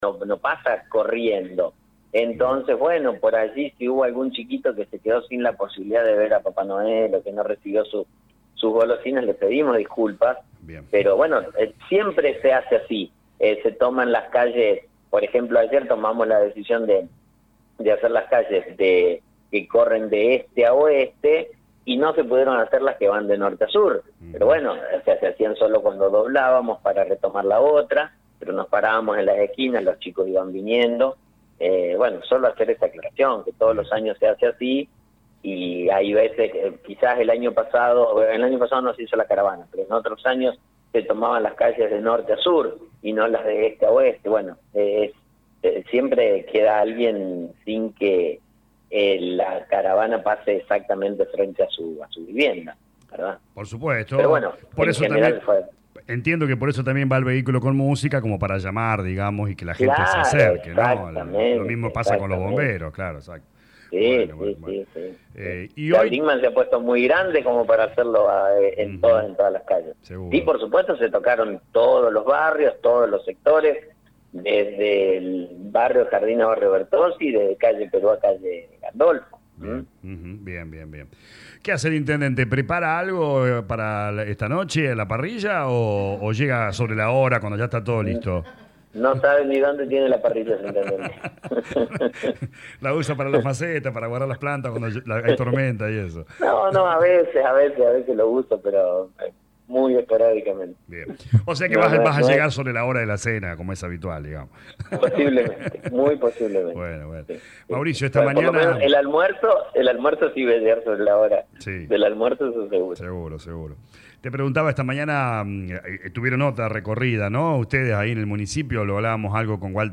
Esta actividad, entre otras, las adelantó el Intendente Mauricio Actis en diálogo con LA RADIO 102.9.